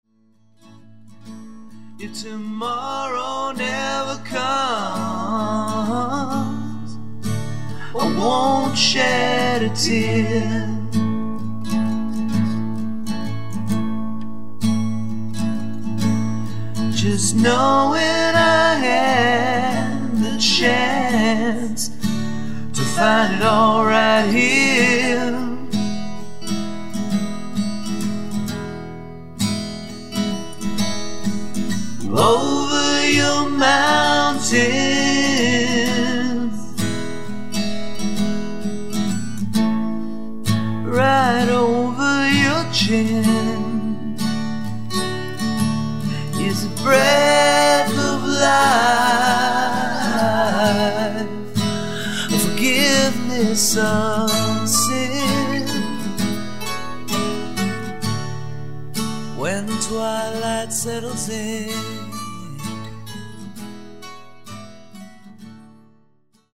an acoustic guitar and PC
lo-fi demos